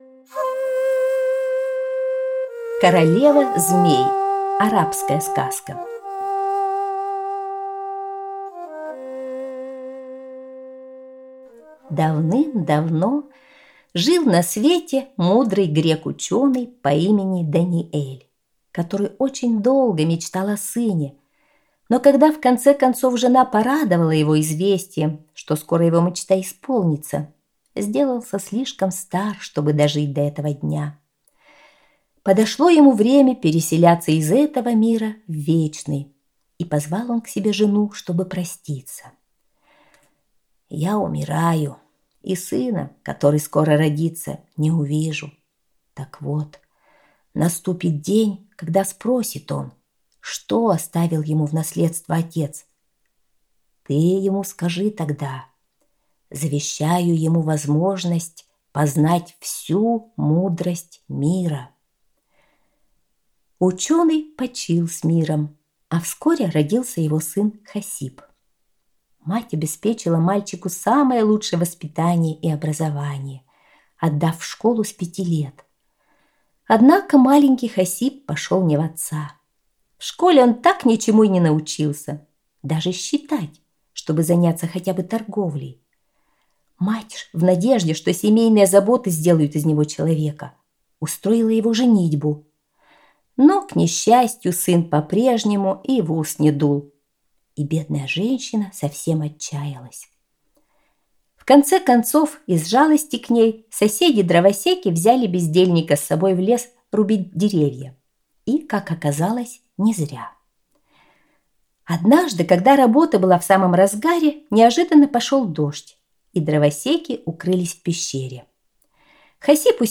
Королева змей - арабская аудиосказка - слушать онлайн